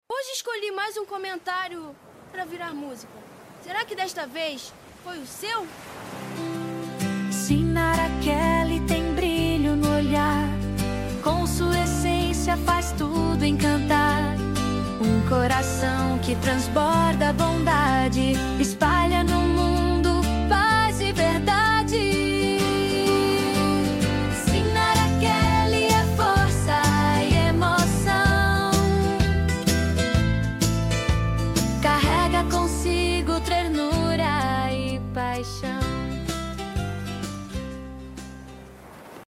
Uma canção linda, cheia de luz, ternura e inspiração.